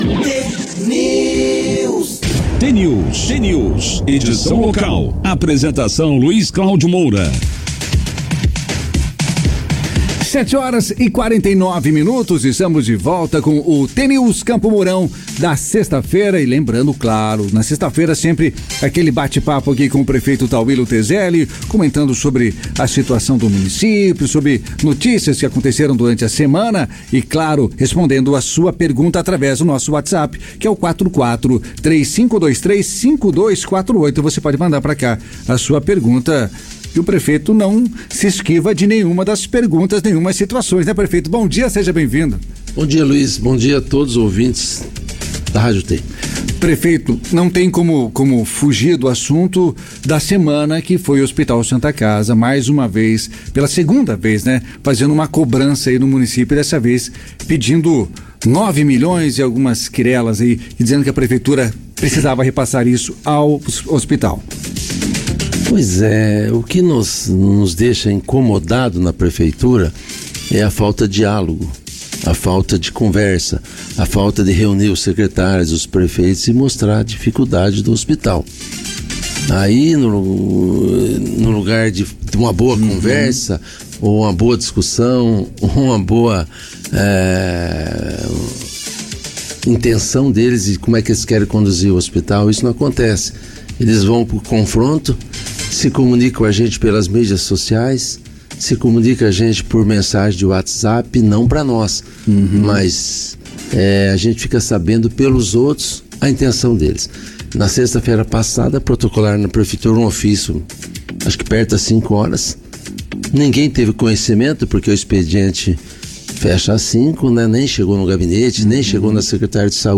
Como faz há mais de duas décadas, nesta sexta-feira, dia 1º, Tauillo Tezelli, atual prefeito de Campo Mourão, participou do jornal T News, da Rádio T FM.
Falta de diálogo da atual diretoria da Santa Casa e obras solicitadas na saída para Cascavel por alunos da Universidade Tecnológica Federal do Paraná (UTFPR), os principais assuntos tratados no programa. Clique no player abaixo e ouça a íntegra da entrevista.